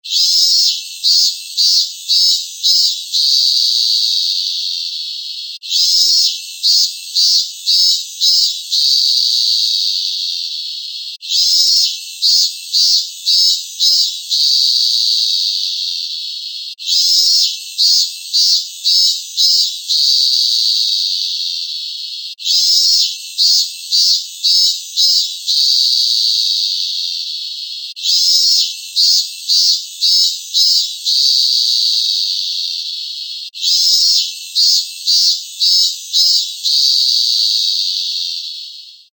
ミンミンゼミの鳴き声 着信音
和名通りの「ミーンミンミンミンミンミー…」という鳴き声がよく知られている。